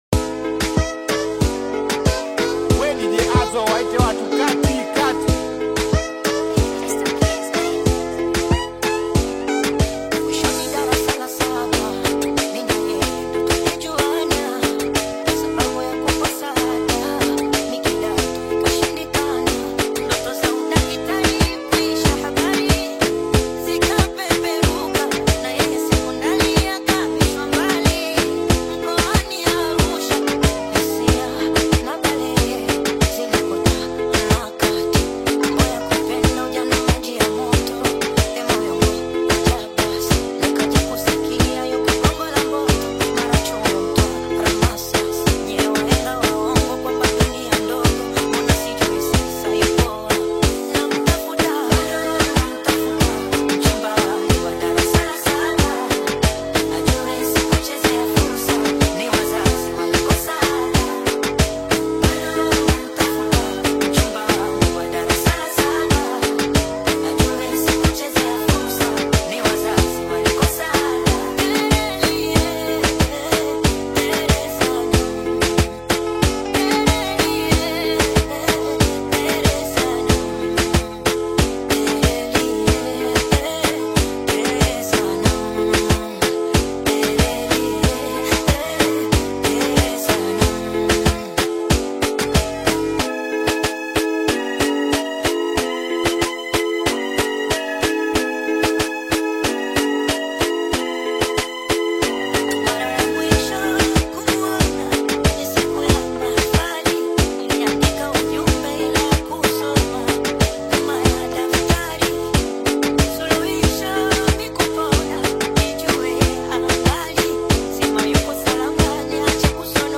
is an electrifying Singeli/Bongo Flava remix